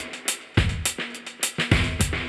Index of /musicradar/dub-designer-samples/105bpm/Beats
DD_BeatB_105-02.wav